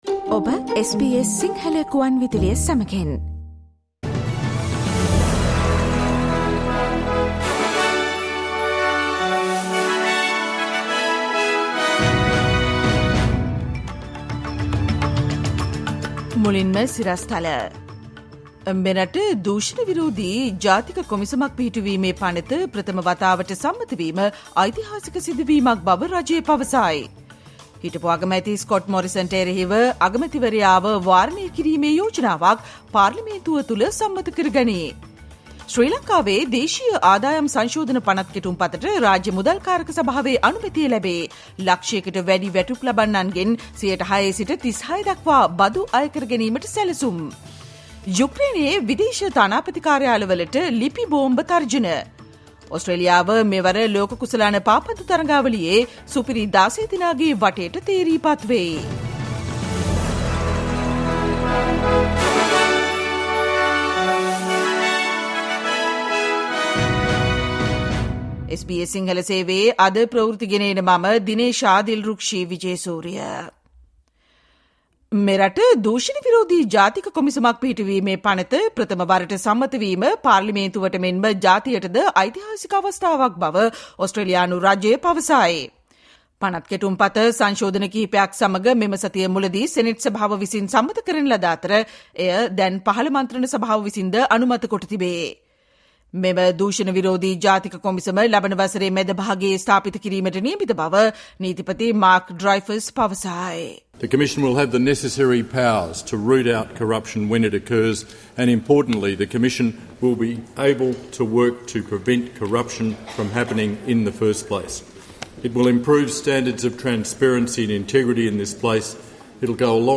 Listen to the SBS Sinhala Radio news bulletin on Thursday 01 December 2022